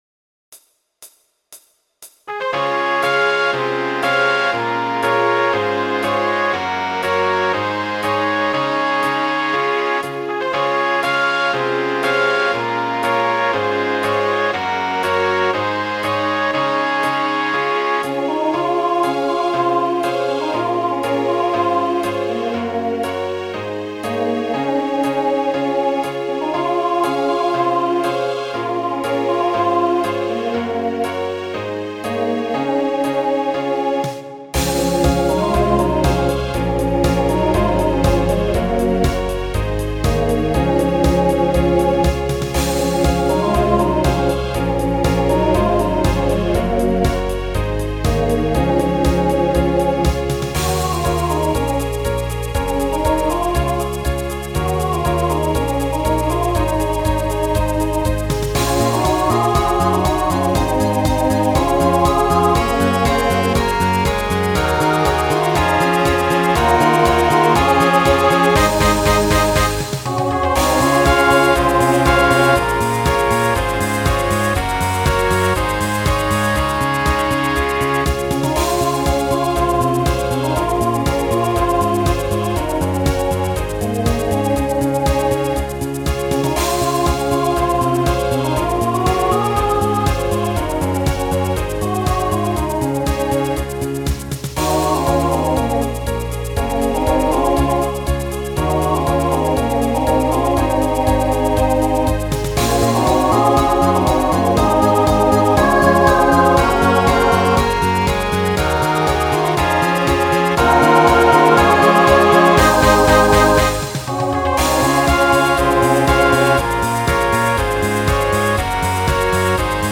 SATB Instrumental combo